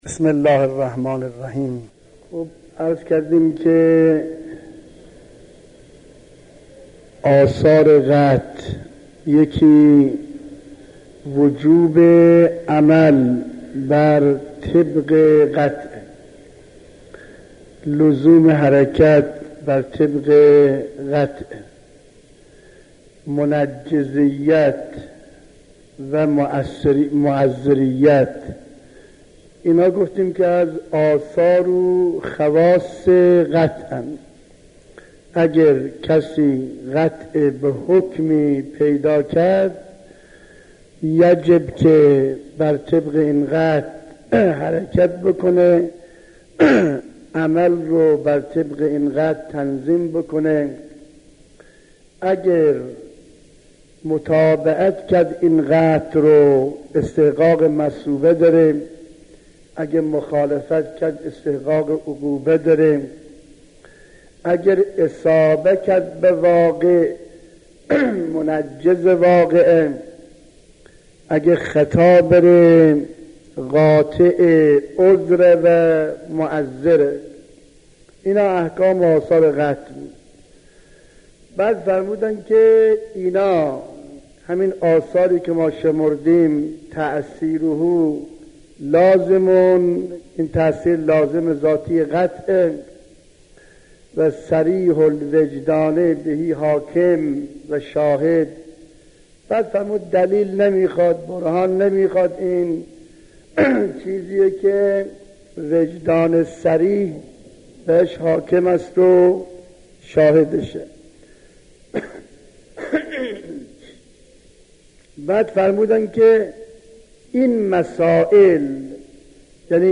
کفايه 2 | مرجع دانلود دروس صوتی حوزه علمیه دفتر تبلیغات اسلامی قم- بیان